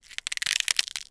ALIEN_Insect_08_mono.wav